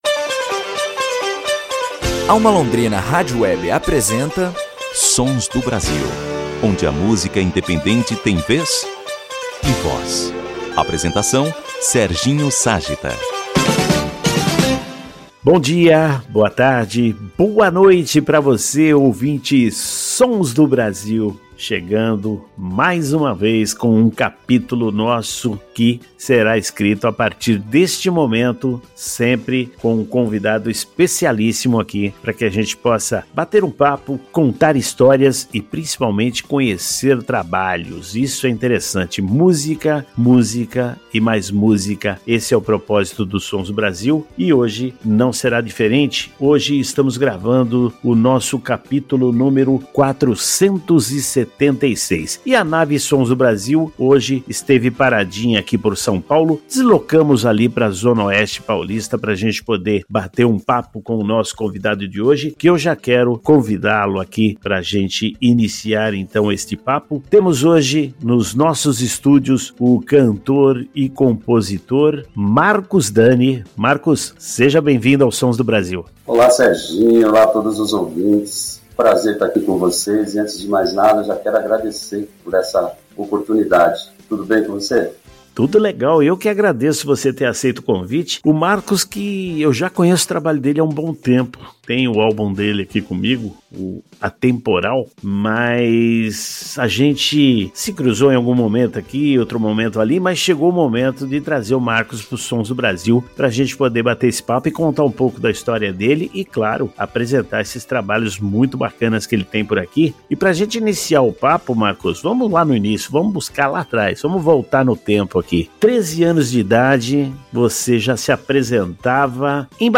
Na entrevista, ele aborda os detalhes de sua jornada musical, os bastidores do álbum “Atemporal” e suas perspectivas para os próximos trabalhos.